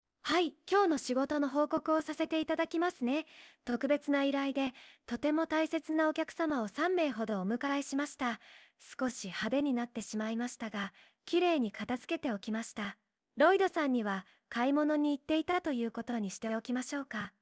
わずか30〜45秒程度の音声サンプルをアップロードするだけで、その人の声質を学習し、同じ声で任意の文章を喋らせることができます
5ボイスクローンの作成